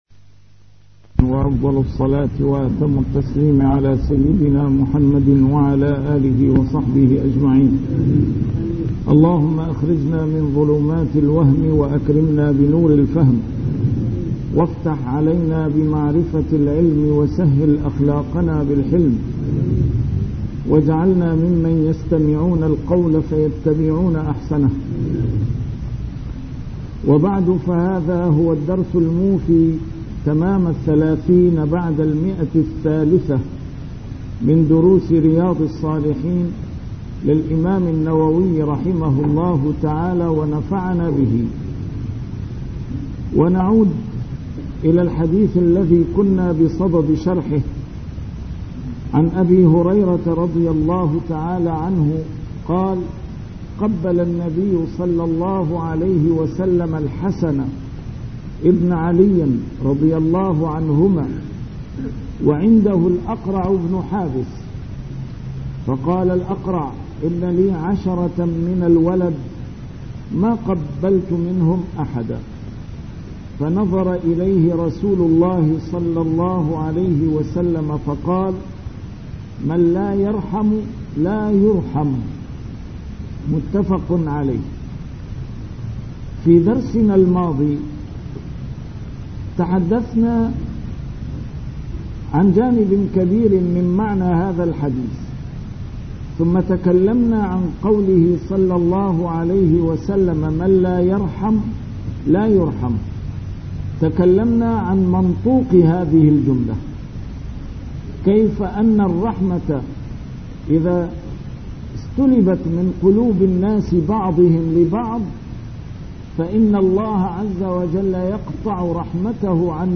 A MARTYR SCHOLAR: IMAM MUHAMMAD SAEED RAMADAN AL-BOUTI - الدروس العلمية - شرح كتاب رياض الصالحين - 330- شرح رياض الصالحين: تعظيم حرمات المسلمين